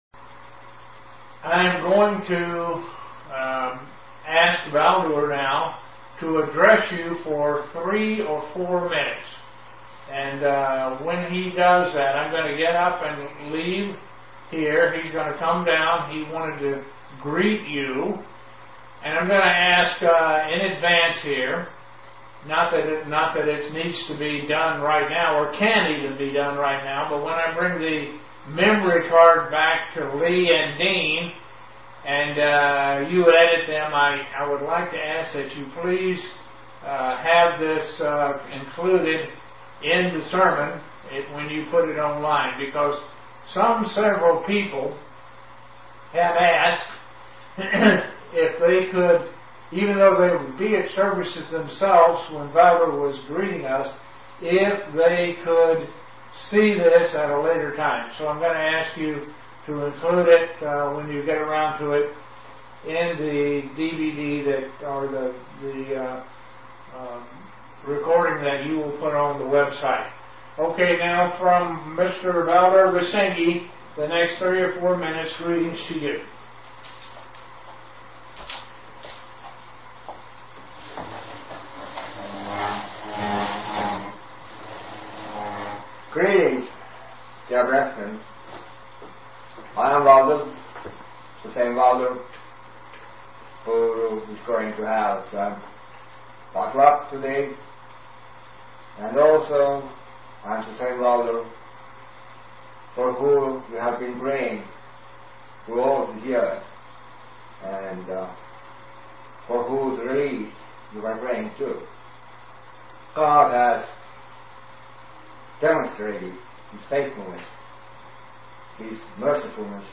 Given in Elmira, NY Buffalo, NY
UCG Sermon Studying the bible?